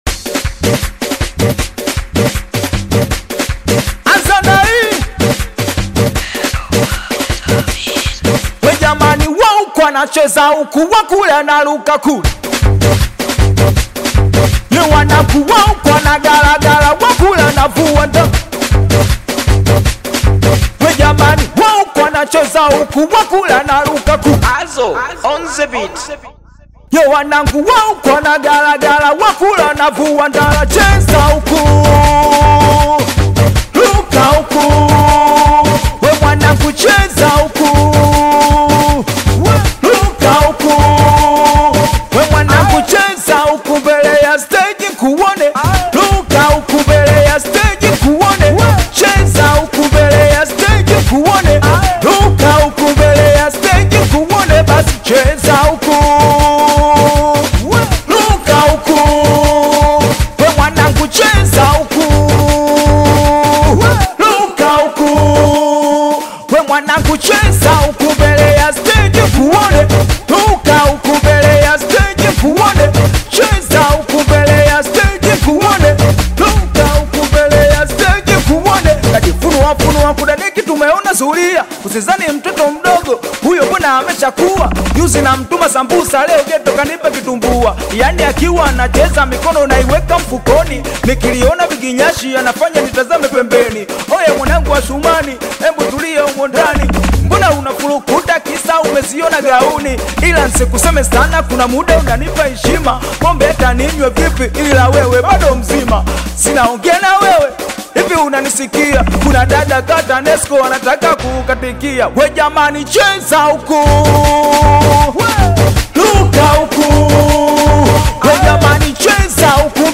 AudioSingeli